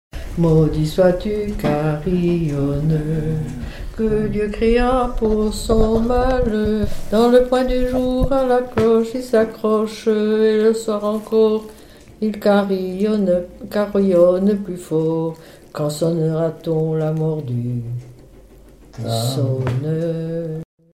Genre brève
Témoignages et chansons
Pièce musicale inédite